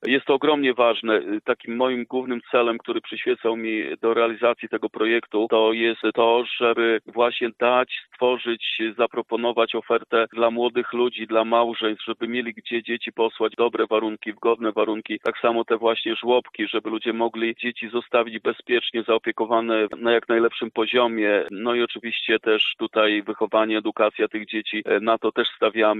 Wójt dodaje, że nowe przedszkola to ważne instytucje, zwłaszcza dla młodych mieszkańców.